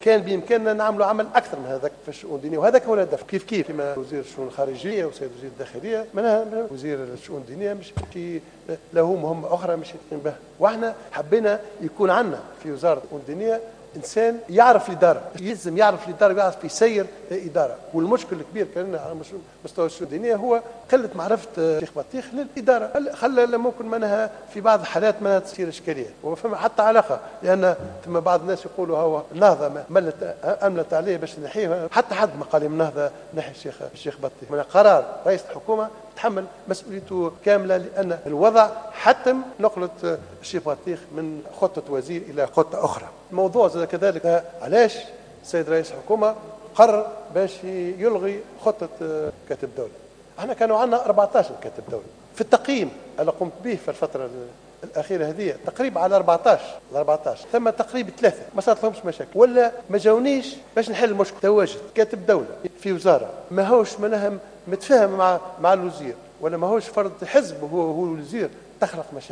قال اليوم الاثنين رئيس الحكومة الحبيب الصيد في رده على اسئلة النواب بمناسبة جلسة لمنح حكومته الجديدة الثقة، إنه لا توجد تدخلات في تغيير وزير الشؤون الدينية عثمان بطيخ في التحوير الوزاري الأخير.